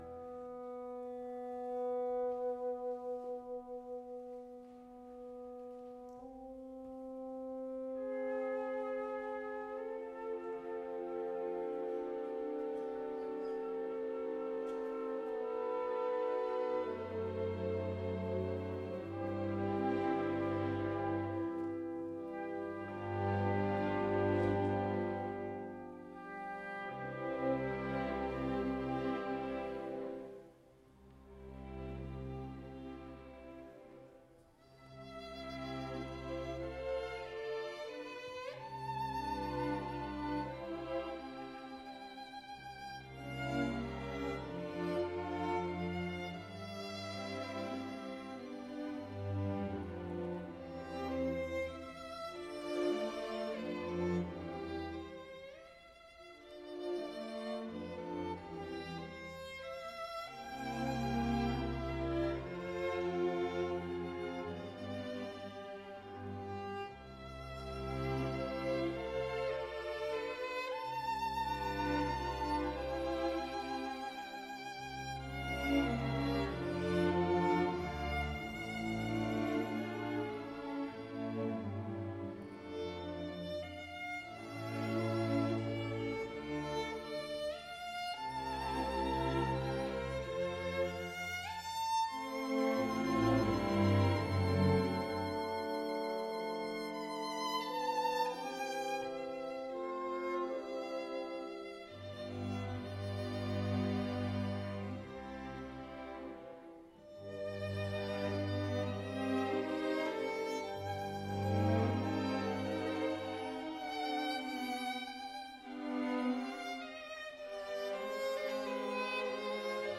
Mendelssohn Violin Concerto in e minor